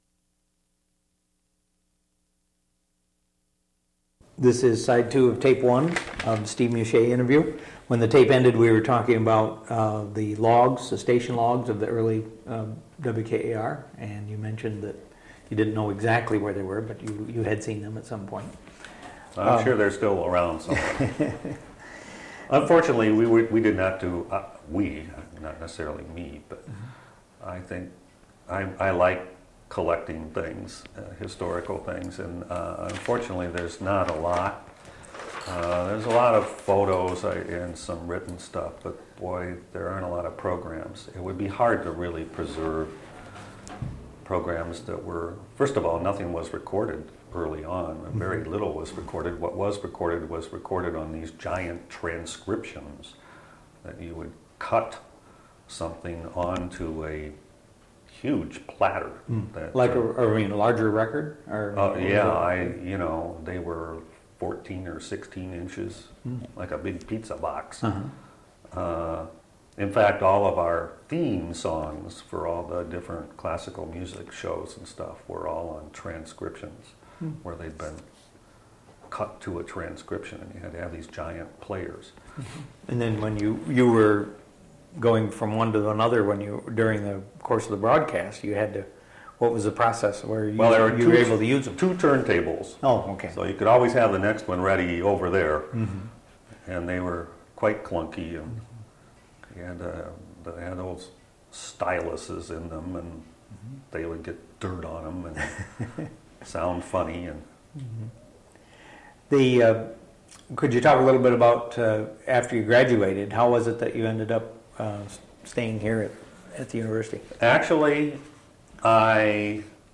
Original Format: Audiocassettes